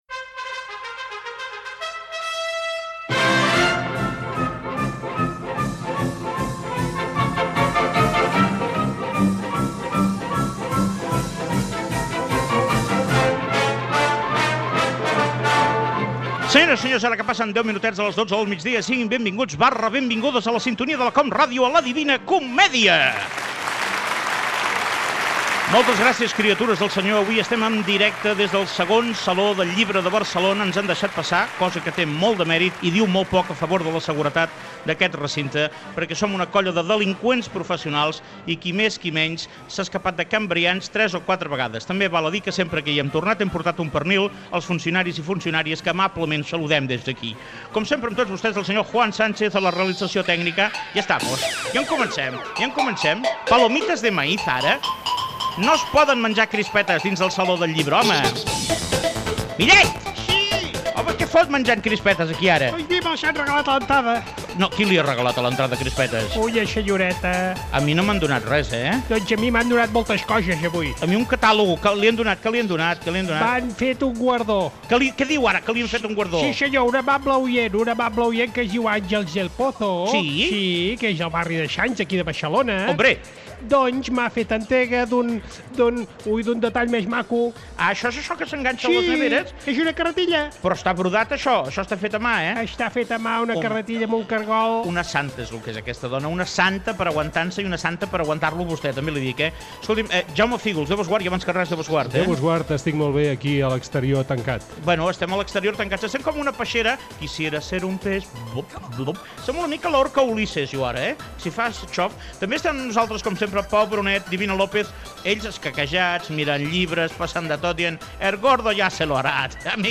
Inici d'un programa emès des del II Saló del Llibre de Barcelona.
Entreteniment
FM